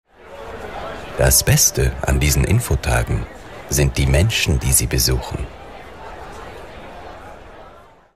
Werbung Hochdeutsch (CH) Hörprobe 02
Sprecher mit breitem Einsatzspektrum.